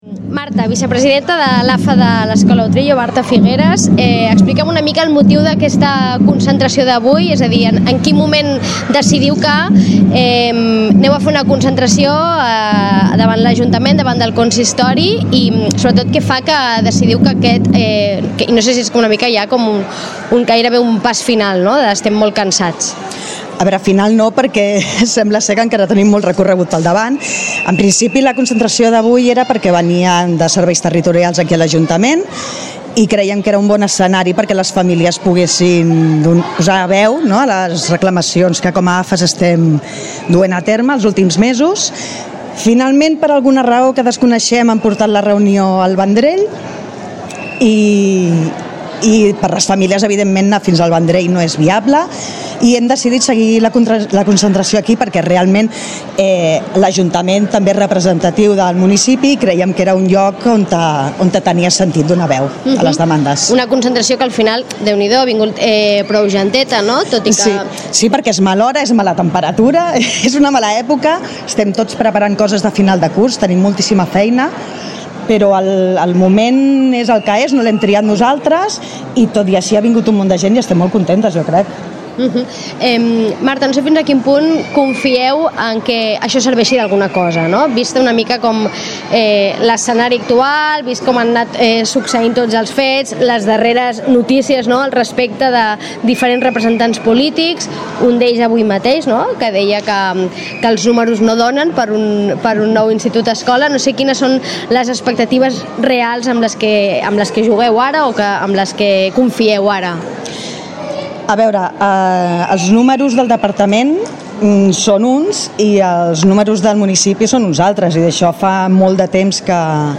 Ràdio Maricel. Emissora municipal de Sitges. 107.8FM. Escolta Sitges.
Un centenar de persones s’han aplegat aquesta tarda davant l’Ajuntament per reclamar un nou institut-escola per a Sitges i recordar que la opció dels barracons per al nou curs escolar no la volen.